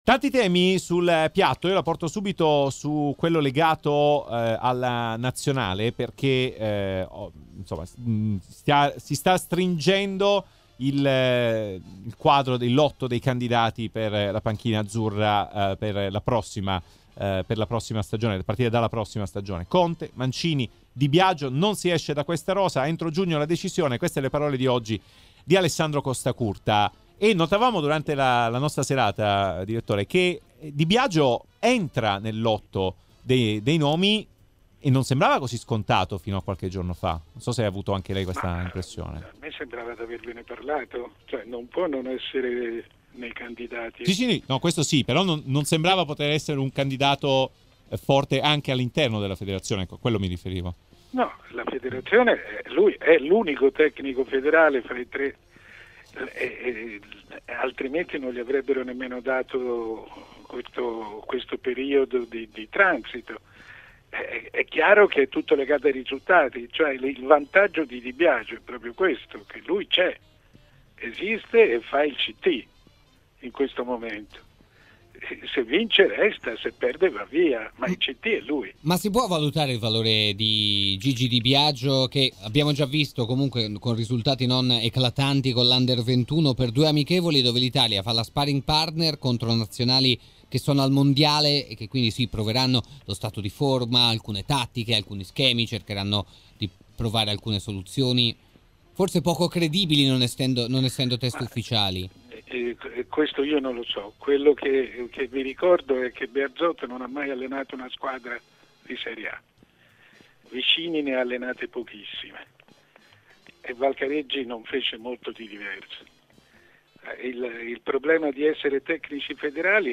Mario Sconcerti, opinionista RMC Sport, sul toto ct per la Nazionale tra Conte, Mancini e Di Biagio.